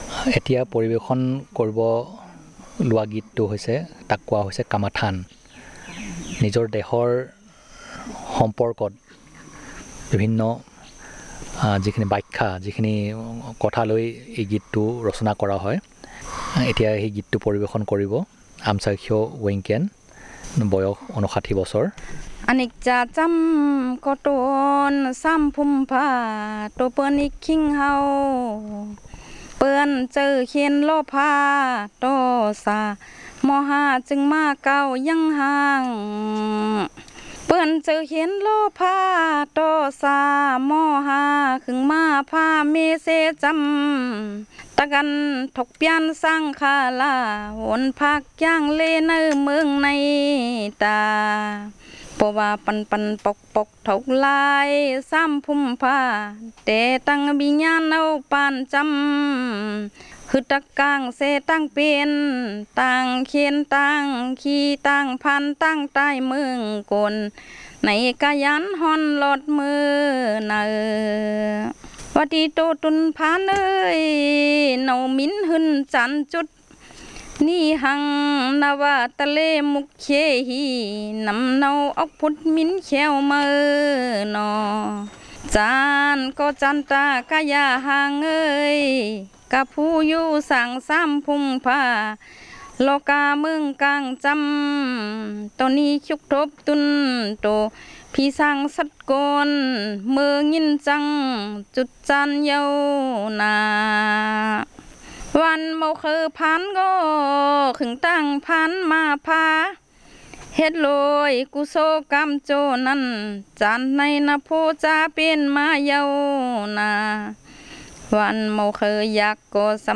Performance of a song about patriotism or love for one's land